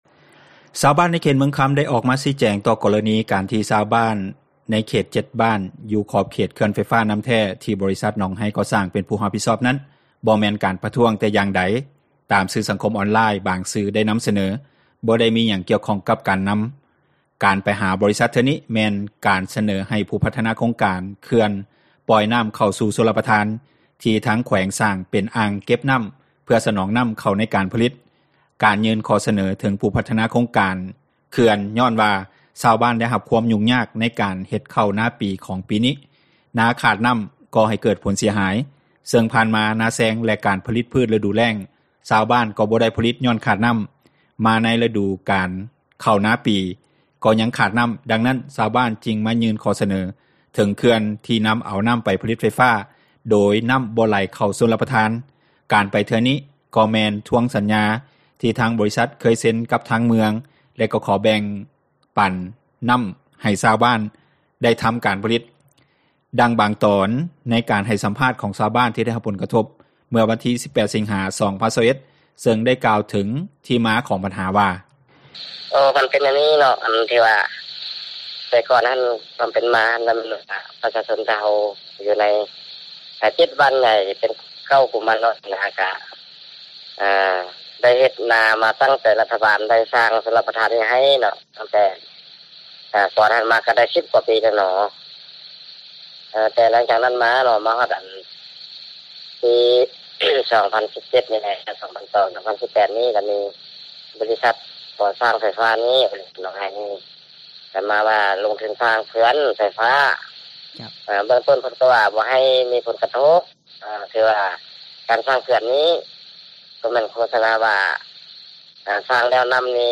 ການໄປເທື່ອນີ້ກໍແມ່ນທວງສັນຍາ ທີ່ທາງບໍຣິສັດເຄີຍເຊັນ ກັບທາງເມືອງ ແລະ ກໍຂໍແບ່ງເປັນນໍ້າໃຫ້ຊາວບ້ານ ໄດ້ທໍາການຜລິດ. ດັ່ງ ບາງຕອນ ໃນການສັມພາດຂອງຊາວບ້ານ ທີ່ໄດ້ຮັບຜົລກະທົບເມື່ອວັນທີ 18 ສິງຫາ 2021 ເຊິ່ງໄດ້ກ່າວ ເຖິງທີ່ມາຂອງບັນຫາວ່າ: